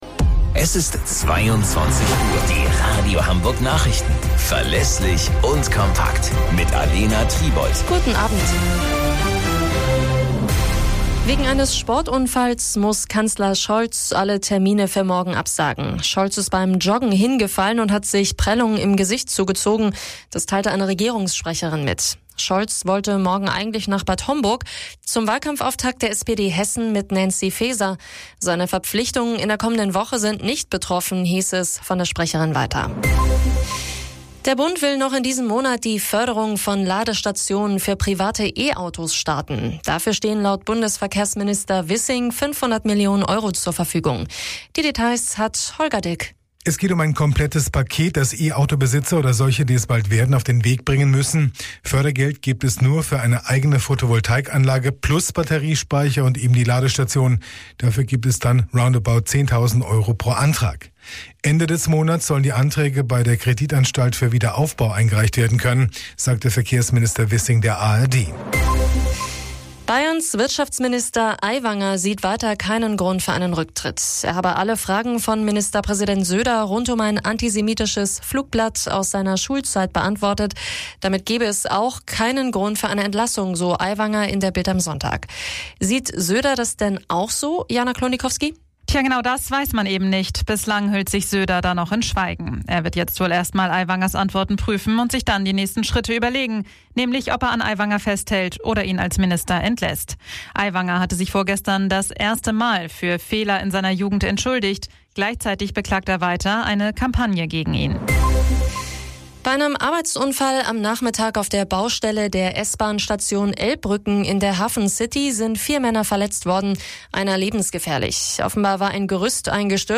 Radio Hamburg Nachrichten vom 03.09.2023 um 02 Uhr - 03.09.2023